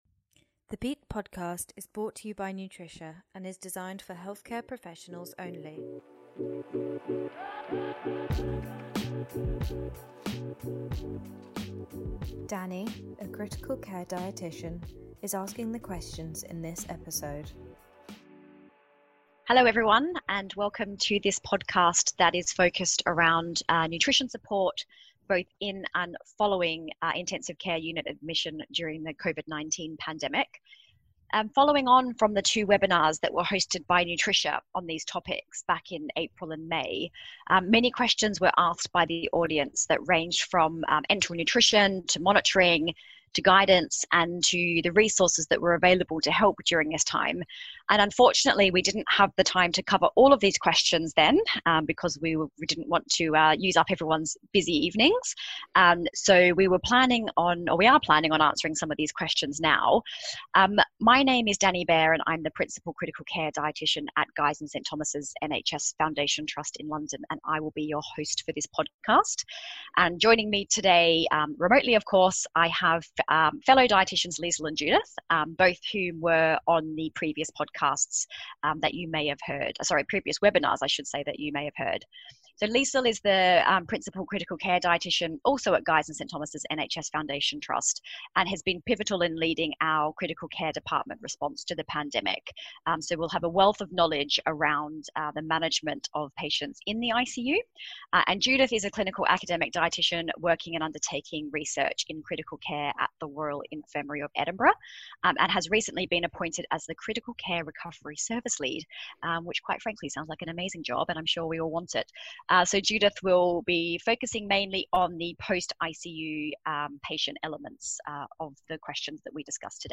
COVID-19: Dietitians Q&A